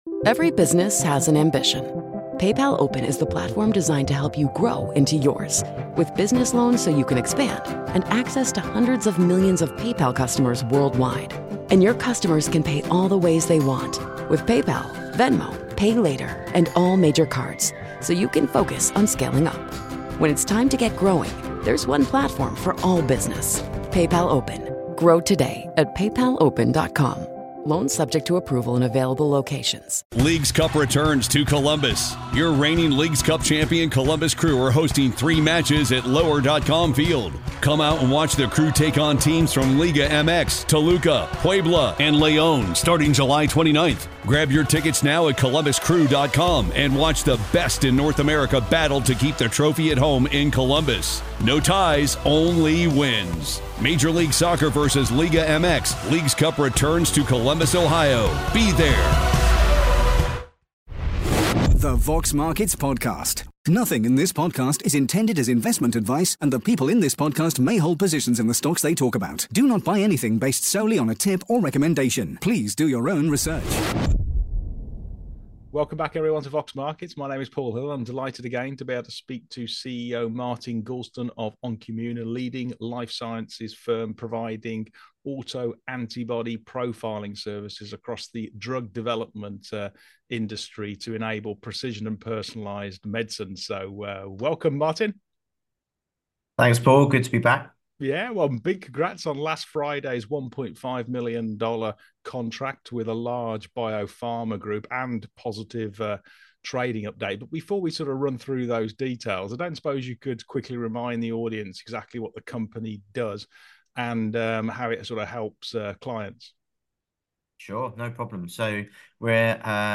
The Vox Markets Podcast / Q&A